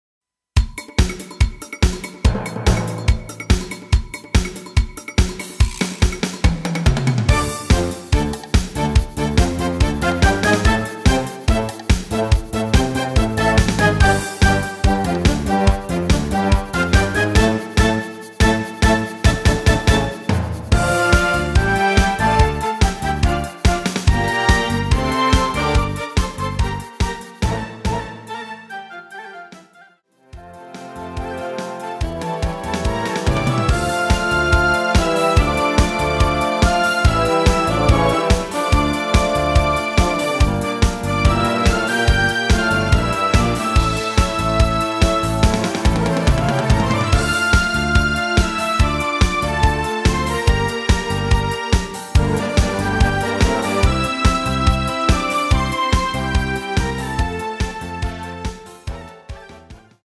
Dm
앞부분30초, 뒷부분30초씩 편집해서 올려 드리고 있습니다.
중간에 음이 끈어지고 다시 나오는 이유는